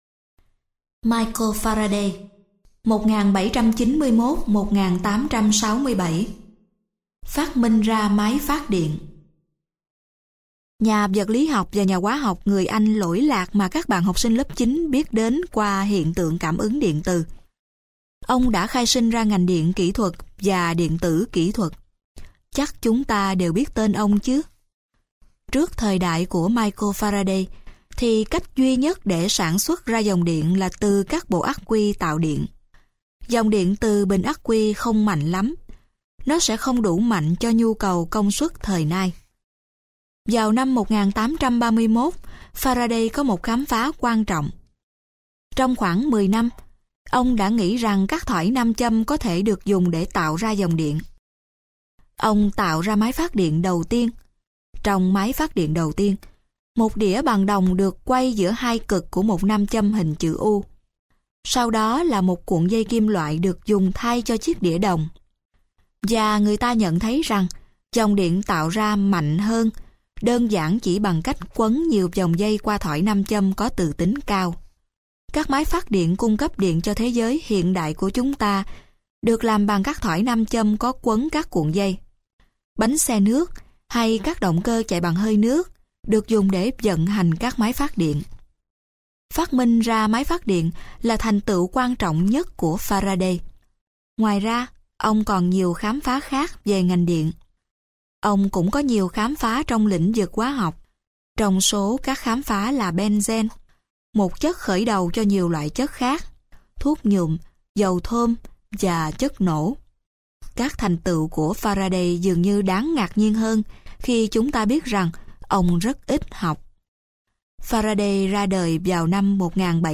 Sách nói Các Nhà Khoa Học Và Những Phát Minh - Sách Nói Online Hay